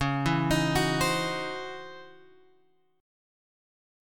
C#mM11 chord {9 7 10 8 7 x} chord